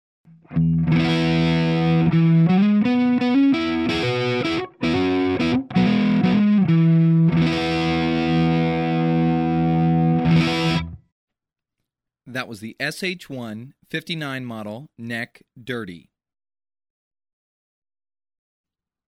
This Seymour Duncan neck humbucker spoons out crystal-clear cleans, full and transparent distortions and singing sustain.
Seymour Duncan SH-1n dirty neck MP3 Audio Unknown
seymour_duncan_sh-1_59_dirty_neck.mp3